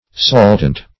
Search Result for " saltant" : The Collaborative International Dictionary of English v.0.48: Saltant \Sal"tant\, a. [L. saltans, p. pr. of saltare to dance, v. intens. fr. salire to leap: cf. F. sautant.